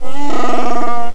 Star Wars - Wookie noise